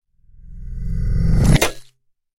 Звуки магнита
Магнит большого размера крепко прилипает к железу